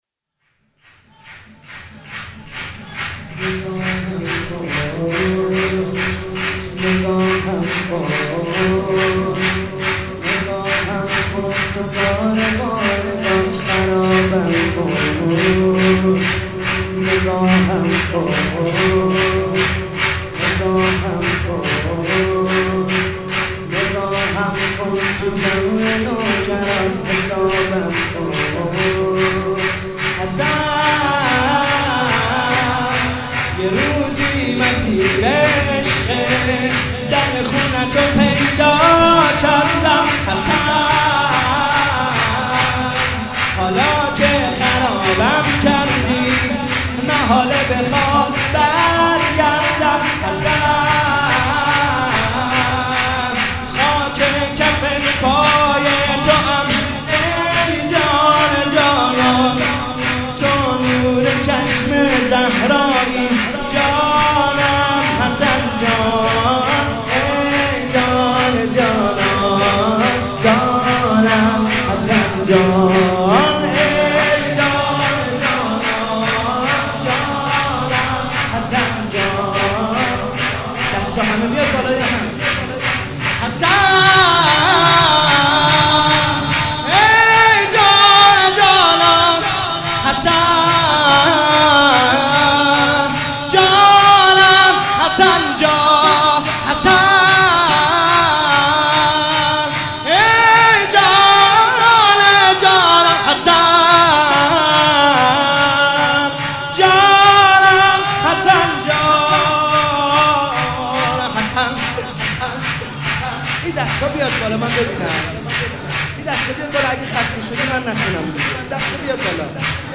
مناسبت : شب شانزدهم رمضان
مداح : کربلایی حسین طاهری قالب : شور